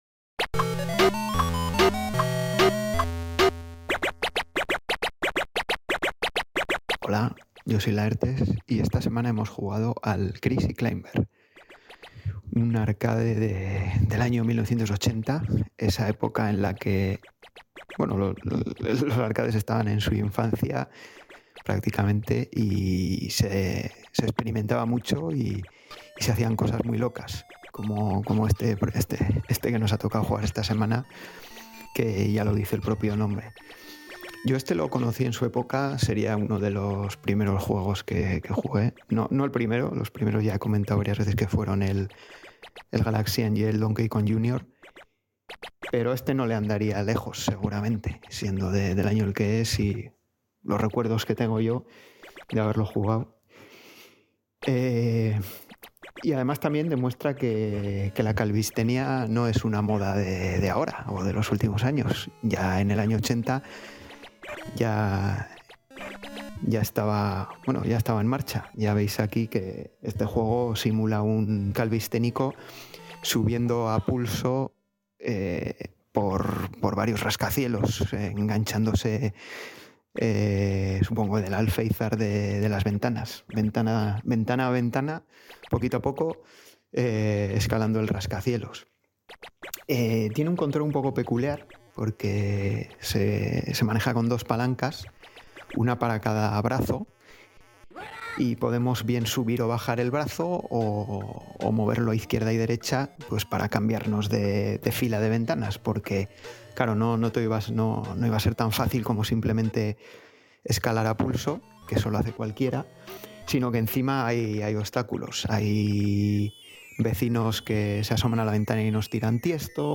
Un sonido que te hace preguntarte si el micrófono estaba enchufado o si era una patata con Bluetooth.